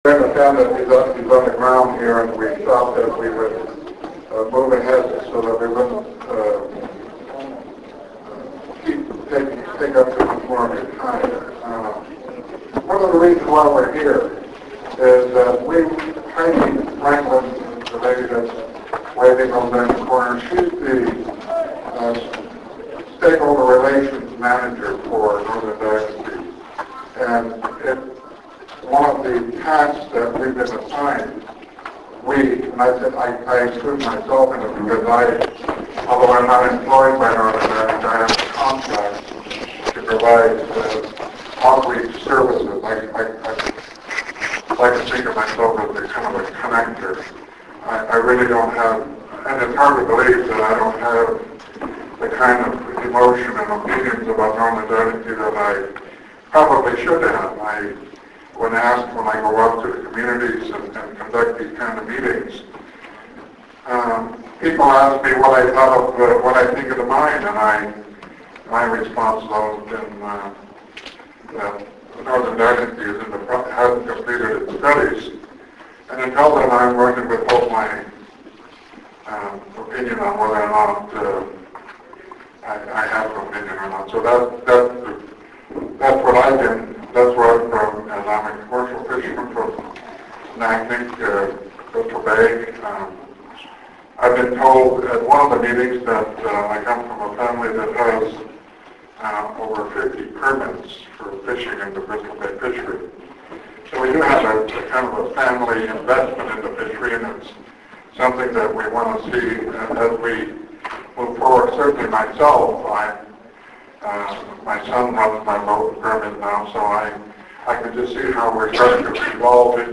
Northern Dynasty Mines meeting review November 15, 2006 presentation to Seattle fishermen
This was a 2-hour presentation filled with a lot of information.